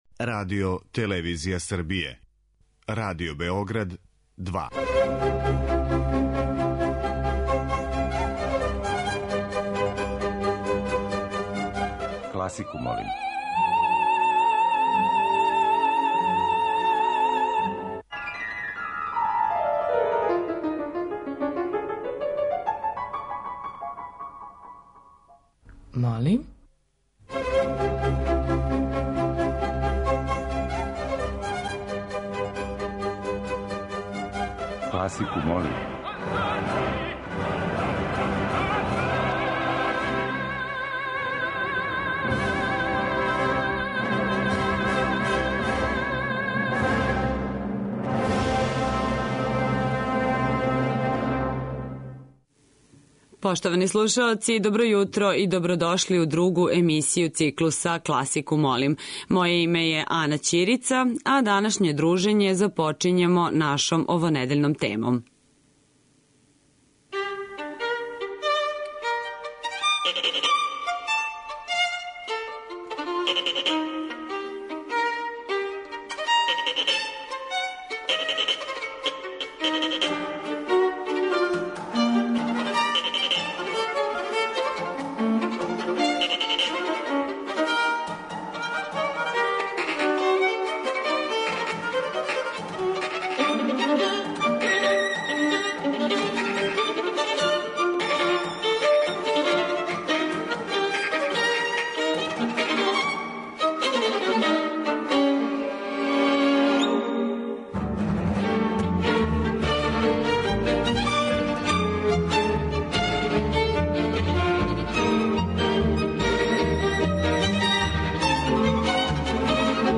учитељ веронауке.